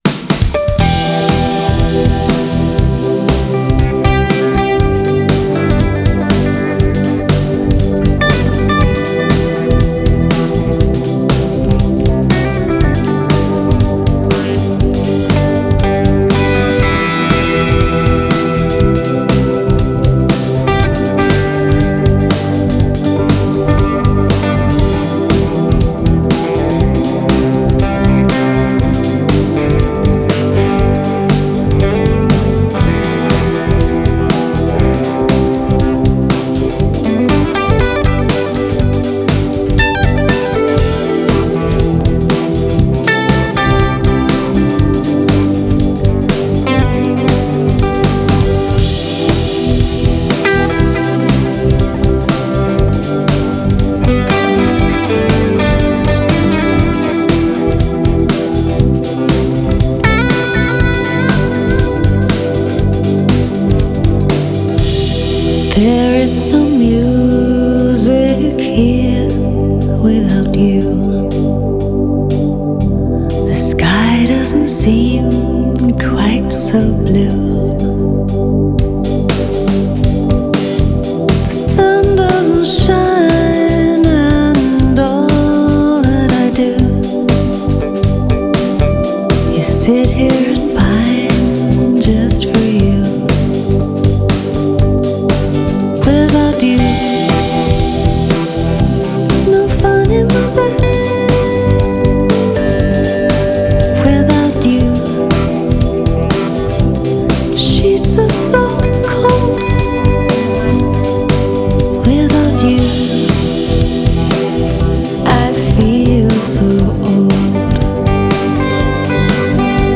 piano and backing vocals
guitar
bass
drums
violin
sax
trombone